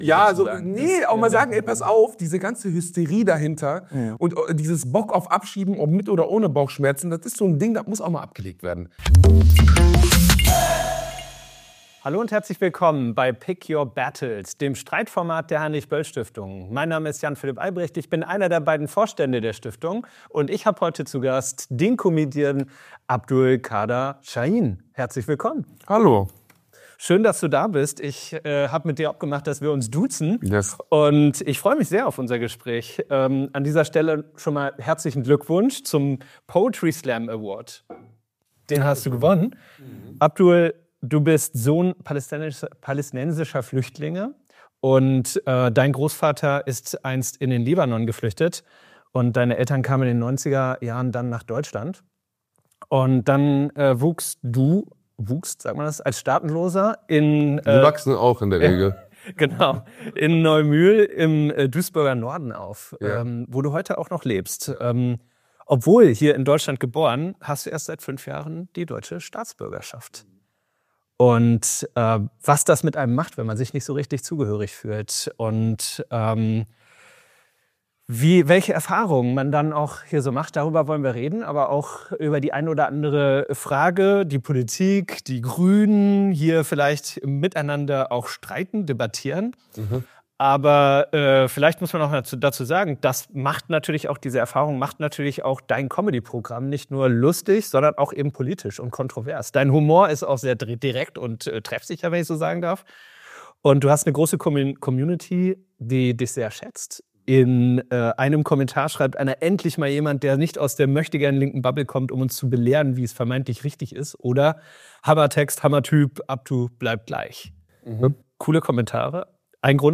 Comedian und Poetry Slamer, im Gespräch mit Jan Philipp Albrecht, Vorstand der Heinrich-Böll-Stiftung.